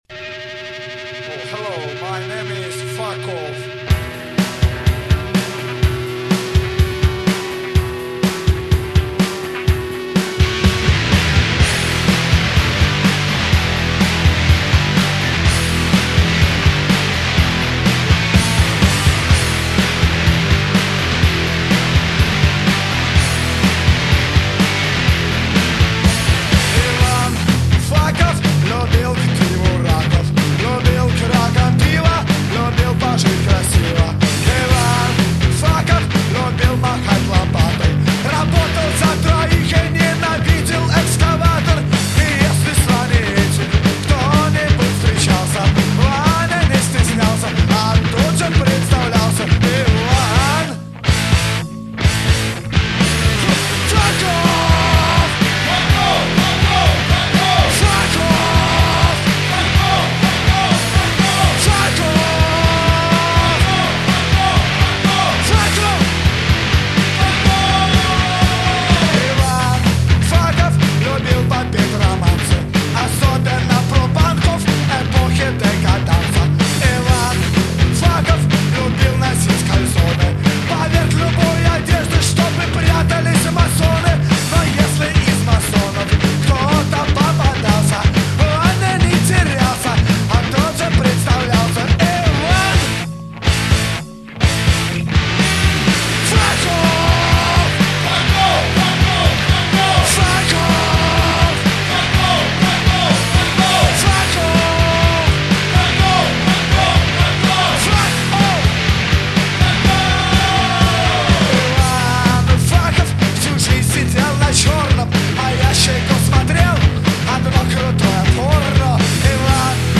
космическая панк-группа